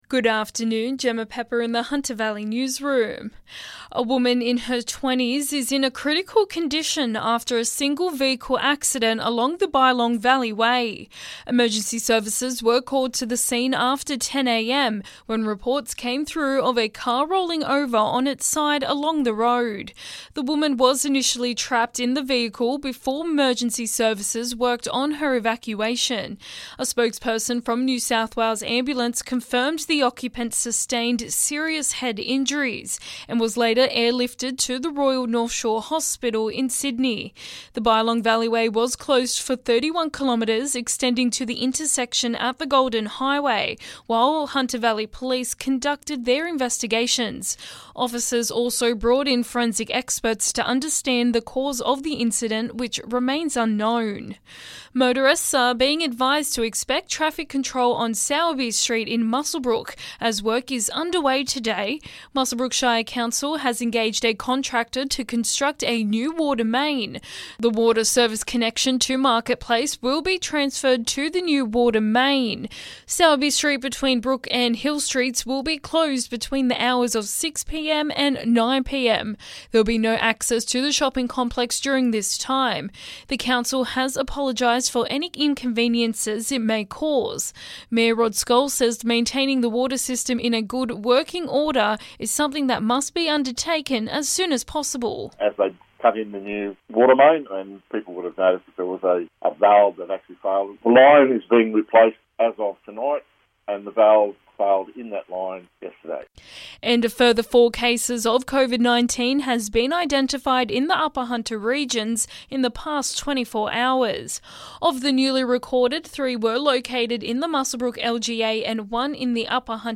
Listen: Hunter Local News Headlines 11/10/2021